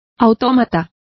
Complete with pronunciation of the translation of automaton.